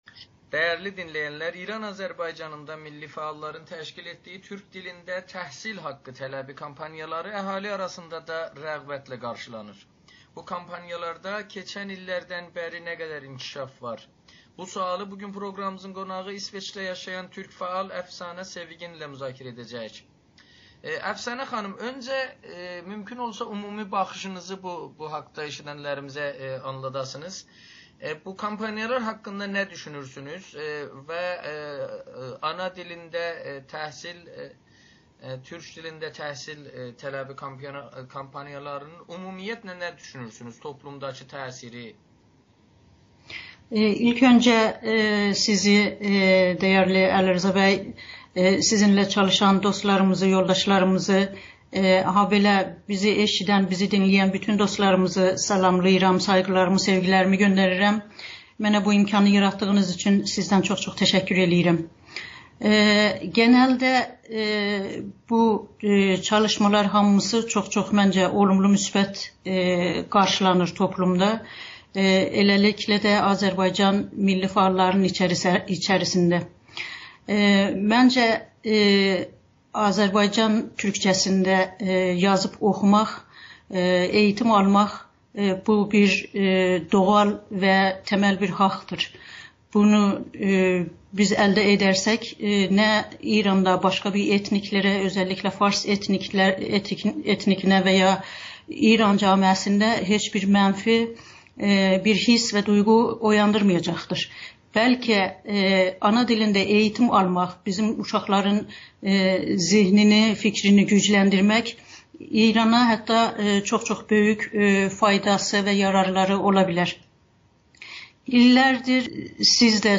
İranda türk dilini gündəmə gətirən siyasətçilər dəstəklənməlidir [Audio-Müsahibə]